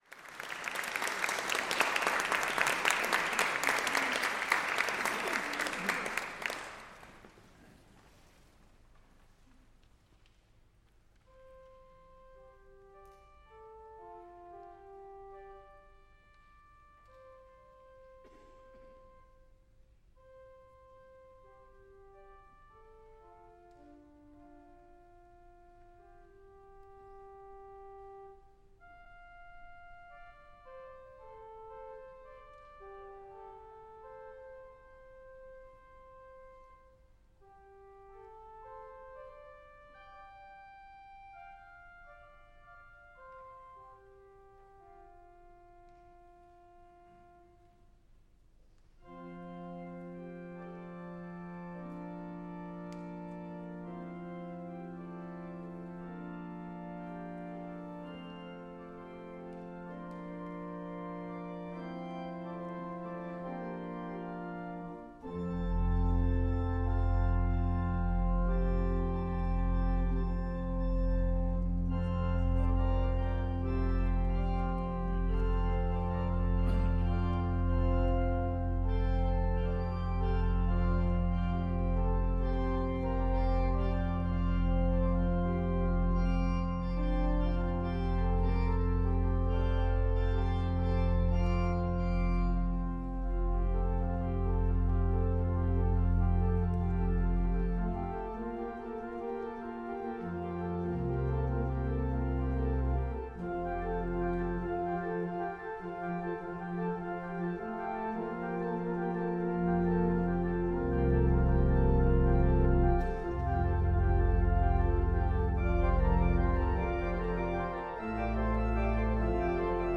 Eltham Choral Society is an amateur, mixed choir of around 90 voices that rehearses and performs in the South East of London, UK.
Details in our events page Or listen to a recording of it from the concert